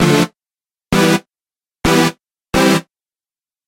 合成器线 " 环境电音循环
标签： 原来 电子 合成器 loopmusic 音乐 效果 氛围 环境 ABLETON 噪音 大气
声道立体声